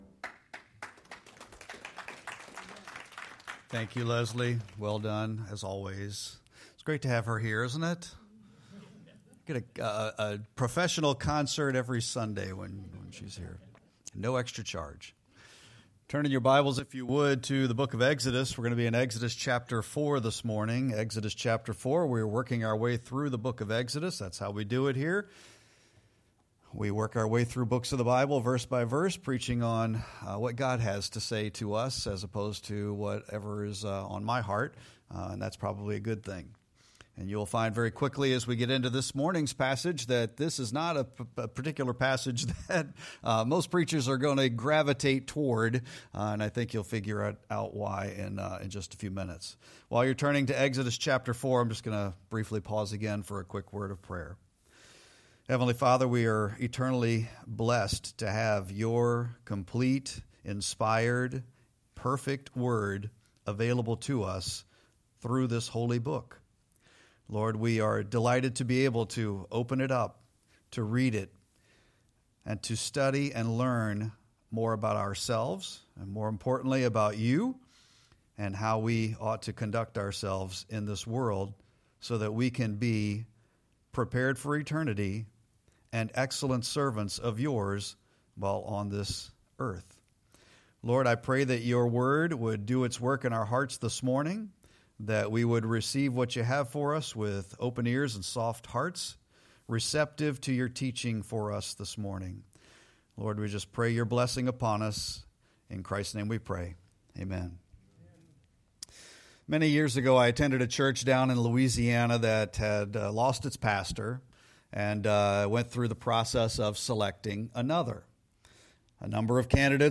Sermon-3-1-26.mp3